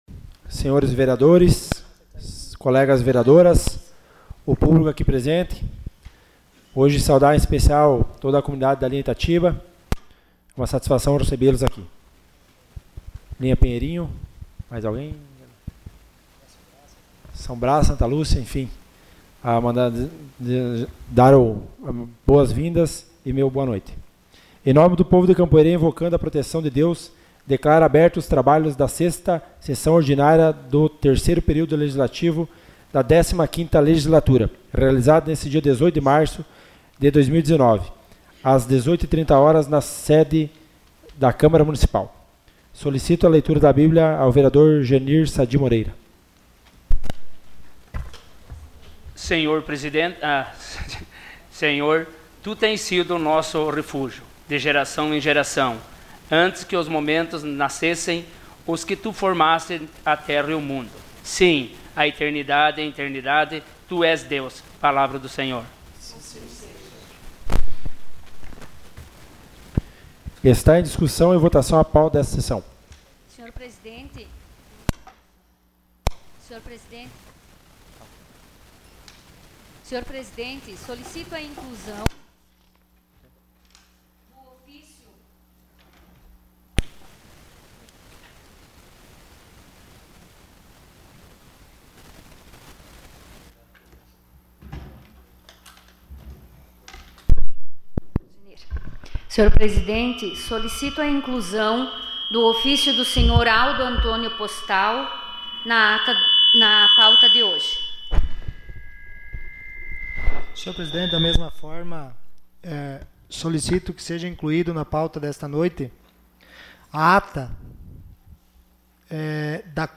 Sessão Ordinária dia 18 de março de 2019.